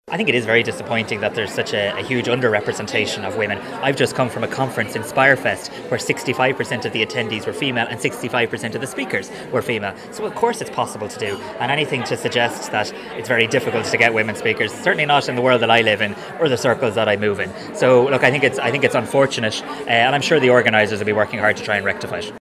Minister Simon Harris says he’d like to see women represented more at all events………….